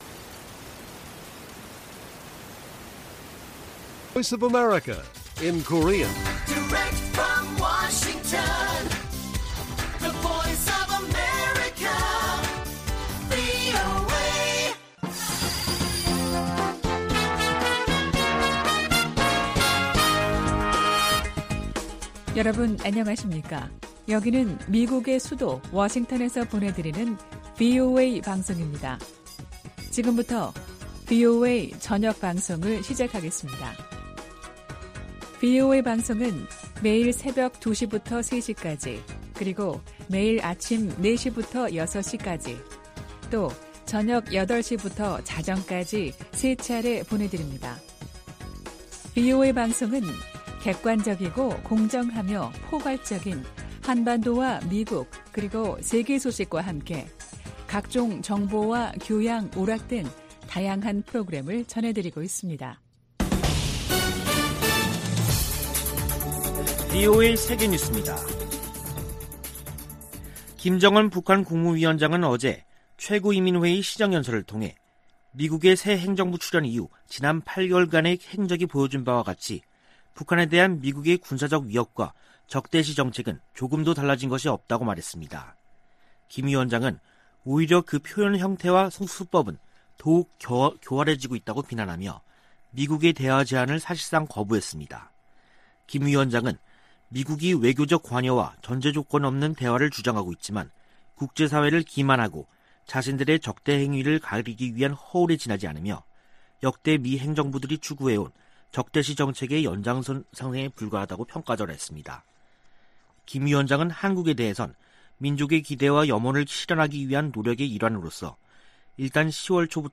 VOA 한국어 간판 뉴스 프로그램 '뉴스 투데이', 2021년 9월 30일 1부 방송입니다. 김정은 북한 국무위원장은 조 바이든 행정부에서도 미국의 대북 적대시 정책이 변한 게 없다며 조건 없는 대화 재개를 거부했습니다. 미 국무부는 북한에 적대적 의도가 없다고 거듭 강조했습니다.